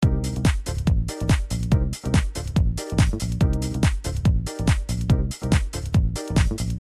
Disco